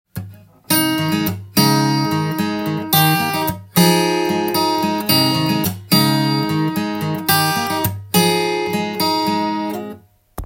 譜面通り弾いてみました
カポタストを１フレットにつけて弾いていきます。